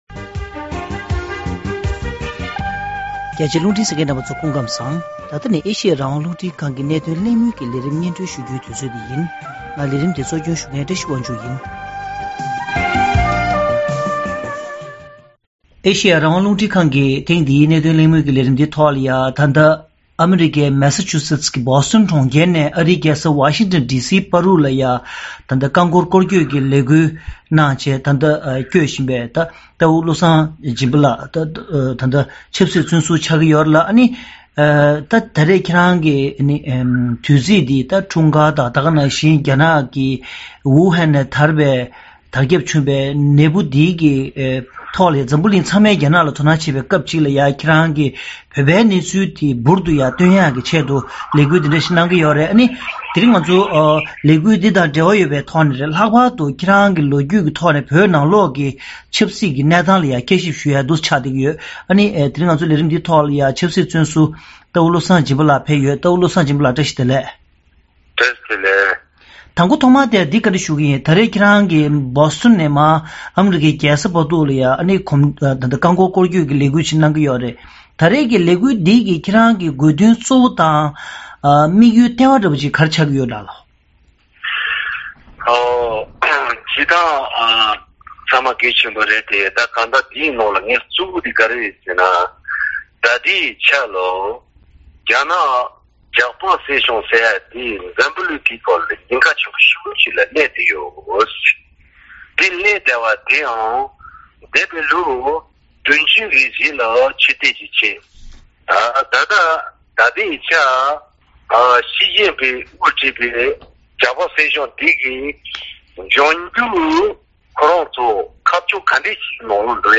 གླེང་མོལ་ཞུས་པ་ཞིག